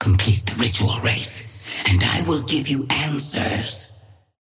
Sapphire Flame voice line - Complete the ritual, Wraith, and I will give you answers.
Patron_female_ally_wraith_start_03.mp3